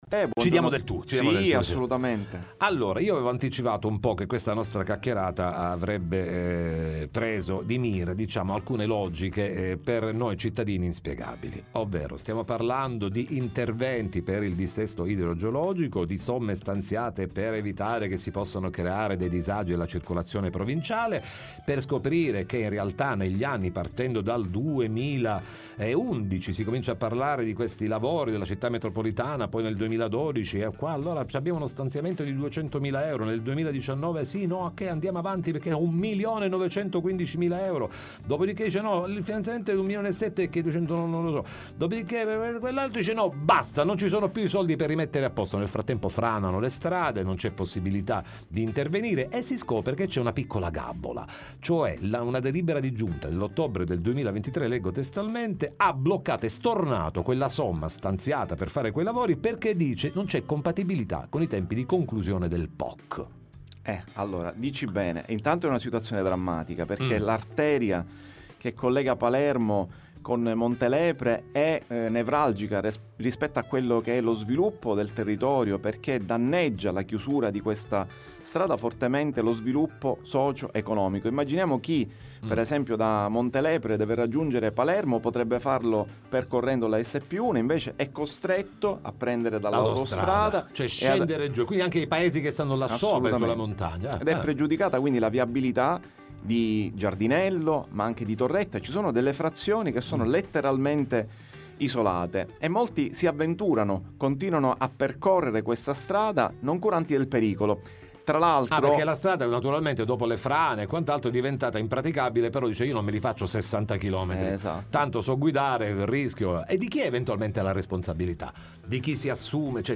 Messa in sicurezza del costone roccioso della SP1, ne parliamo con l’On. Giambona nei nostri studi